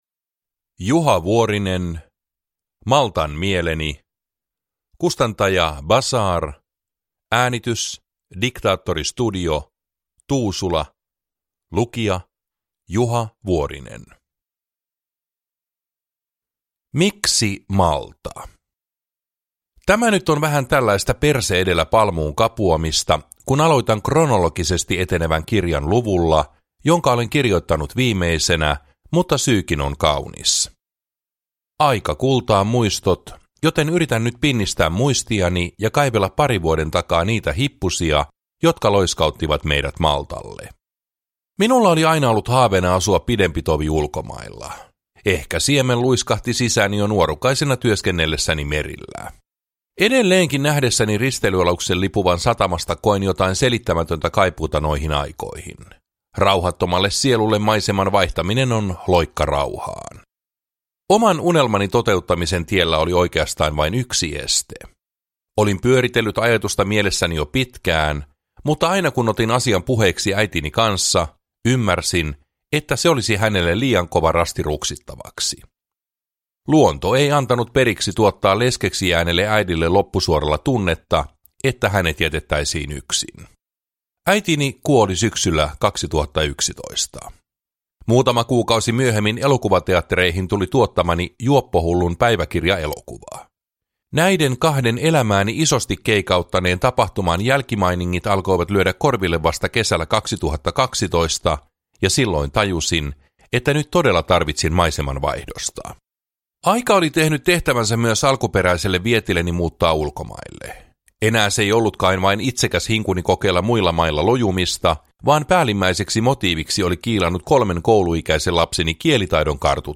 Uppläsare: Juha Vuorinen